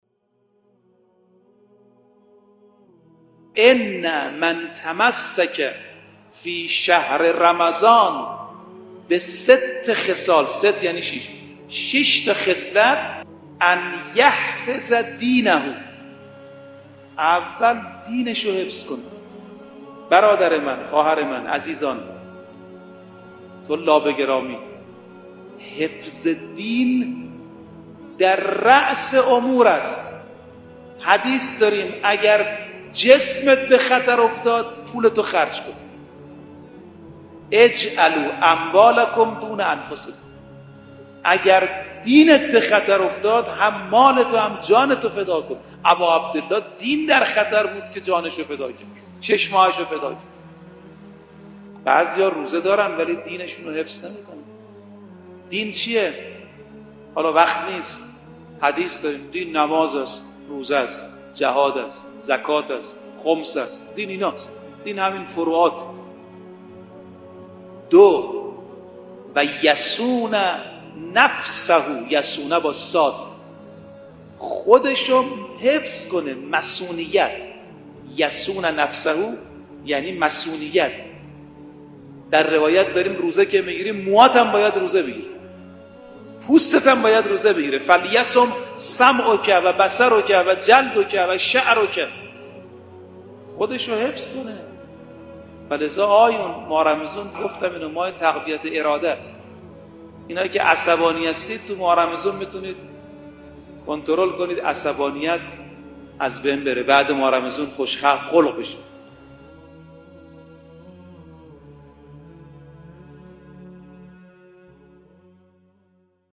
ماه مبارک رمضان، فرصتی مغتنم برای استفاده از فیوضات میهمانی پروردگار است که می‌بایست قدر آن را به خوبی درک کنیم. حجت‌الاسلام والمسلمین رفیعی در یکی از سخنرانی‌های خود به موضوع «حفظ دین در ماه خدا» پرداخته است که تقدیم مخاطبان گرامی می‌شود.